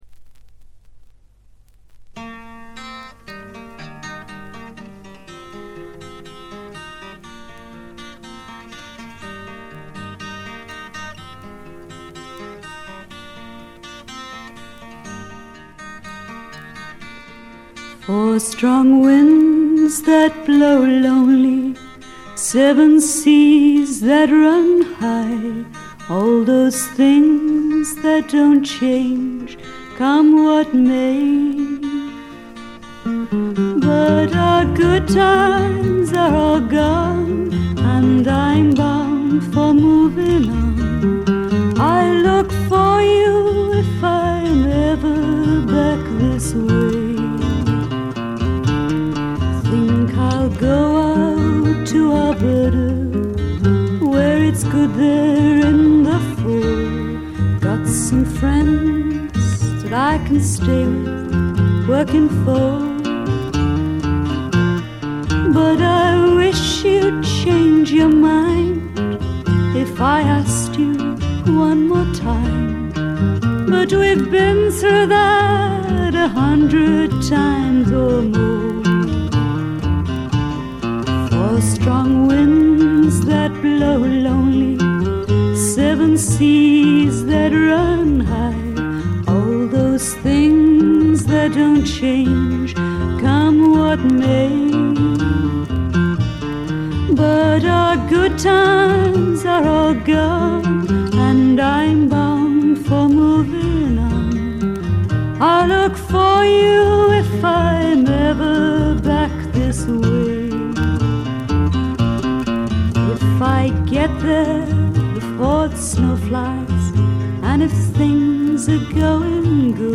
軽微なバックグラウンドノイズ程度。
英国フィメール・フォークの大名作でもあります。
内容はというとほとんどがトラディショナル・ソングで、シンプルなアレンジに乗せた初々しい少女の息遣いがたまらない逸品です。
モノラル盤です。
試聴曲は現品からの取り込み音源です。